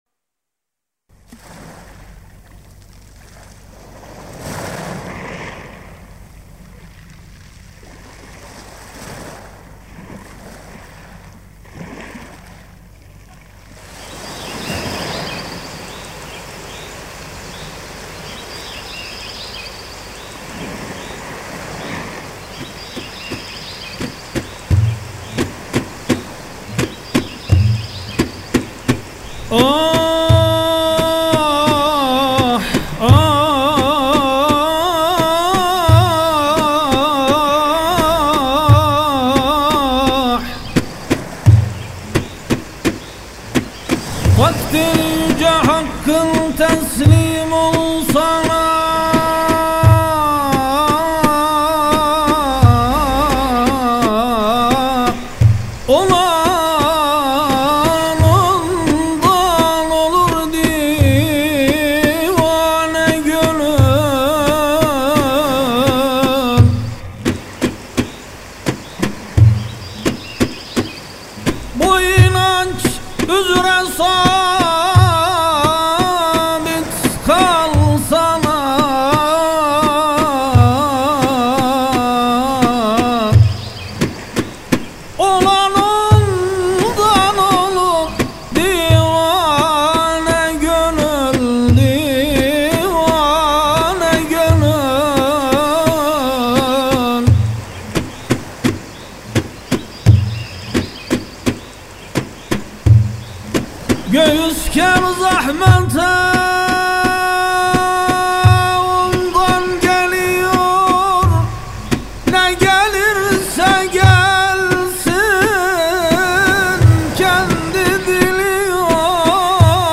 İlahiler